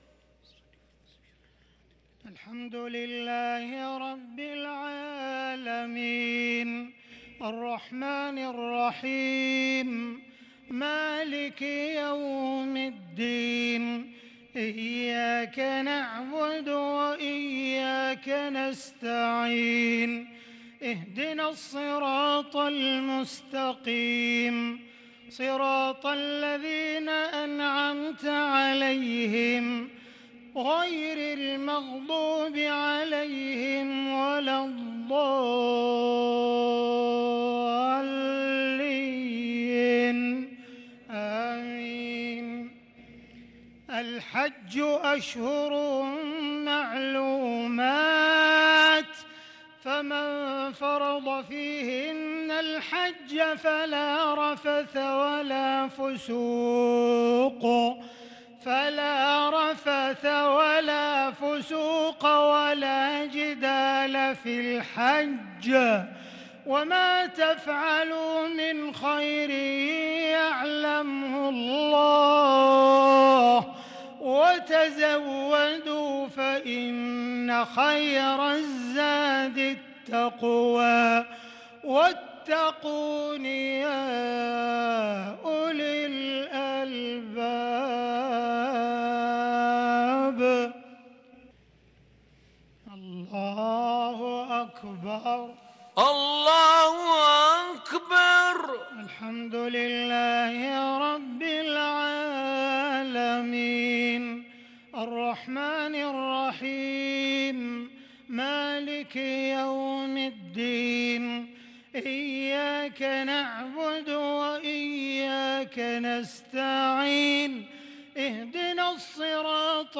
صلاة العشاء للقارئ عبدالرحمن السديس 19 ذو القعدة 1443 هـ
تِلَاوَات الْحَرَمَيْن .